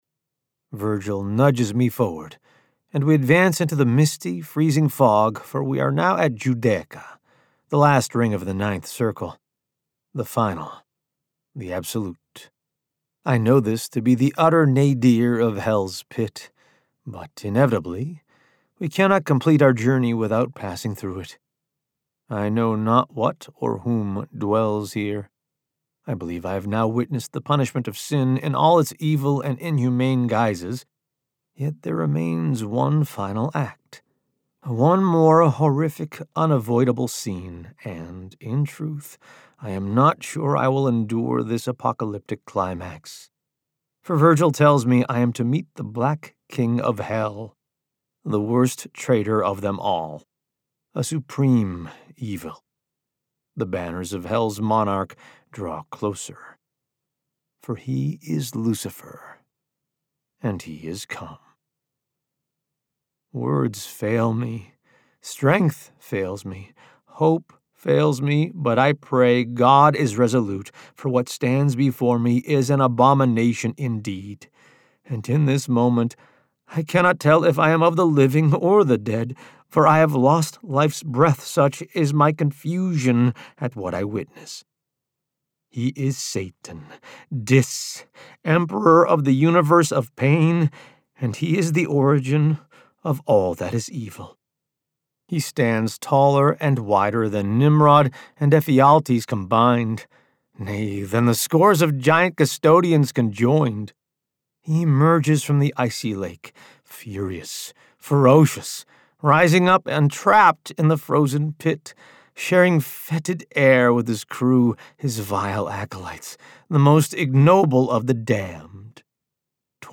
AUDIOBOOK PREVIEW